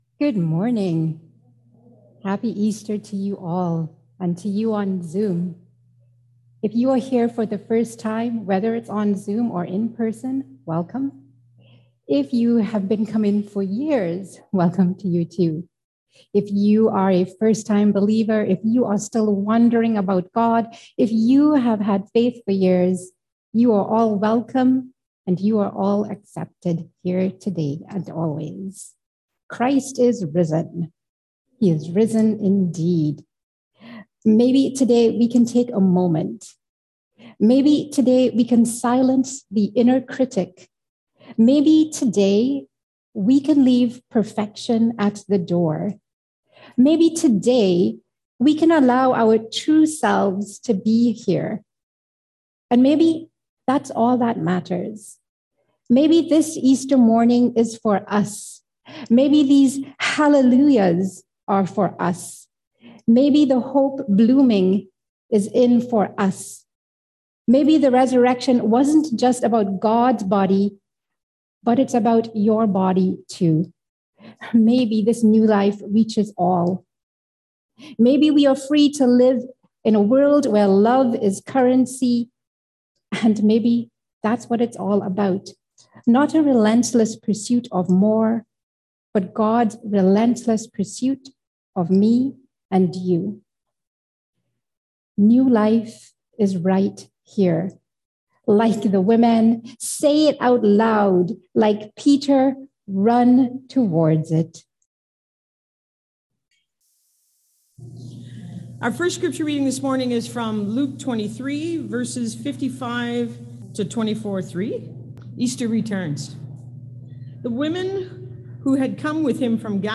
Luke 23:55- 24:3 Service Type: Easter Sunday « Identity Labels Good Friday 2022